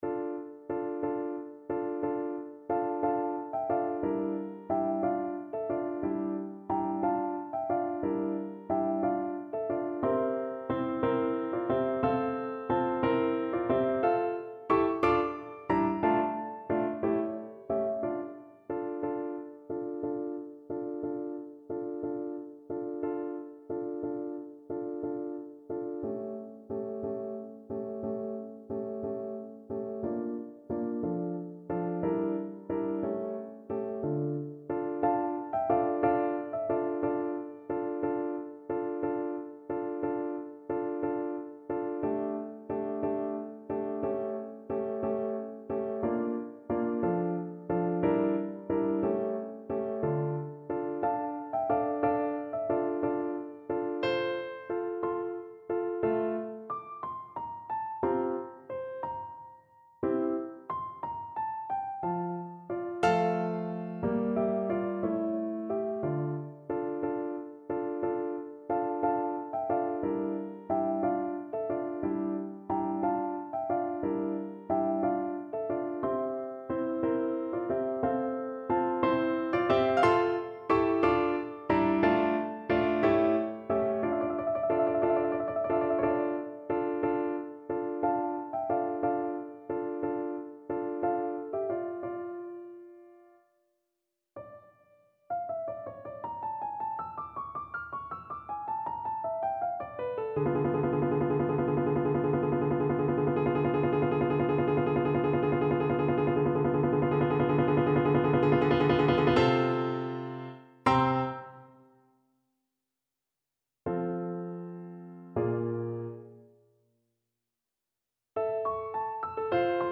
6/8 (View more 6/8 Music)
Andantino (.=60) (View more music marked Andantino)
Classical (View more Classical Flute Music)